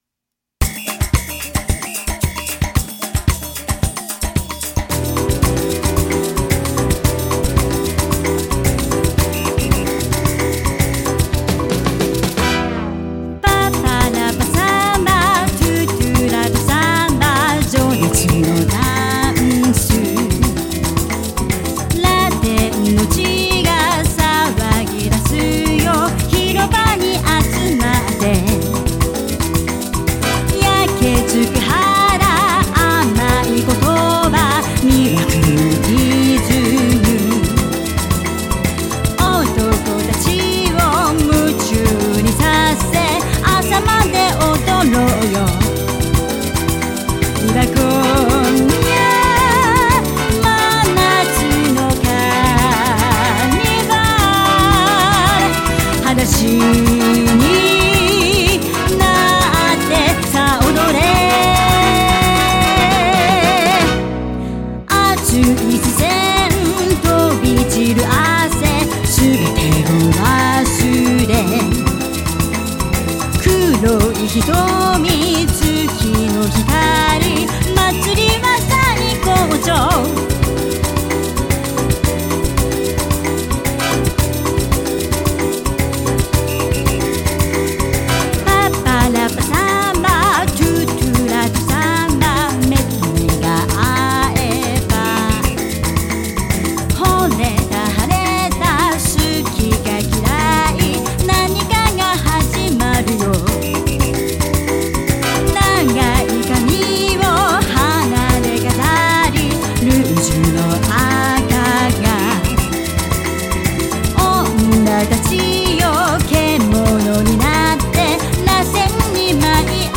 Fascinating Social Dance Music - Танцевальная коллекция